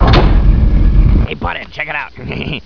doormv1.wav